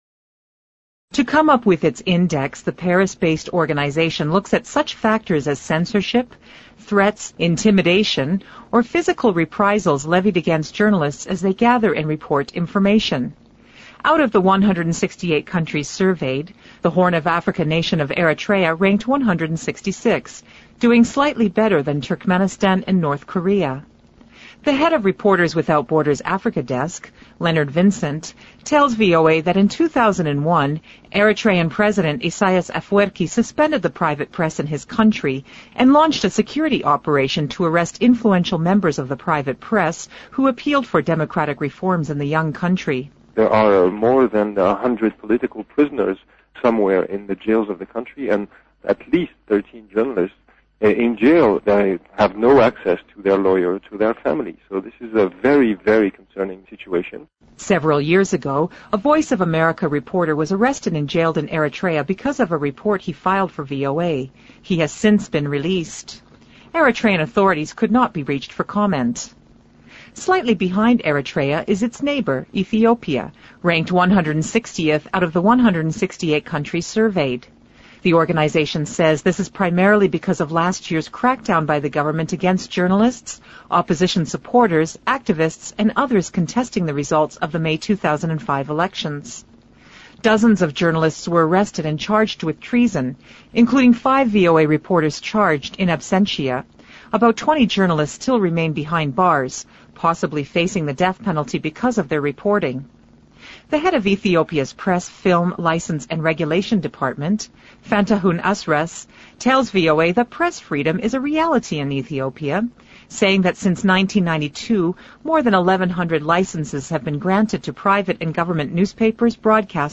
2006年VOA标准英语-Eritrea, Ethiopia Ranked Among Worst Press Viol 听力文件下载—在线英语听力室